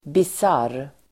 Ladda ner uttalet
Uttal: [bis'ar:]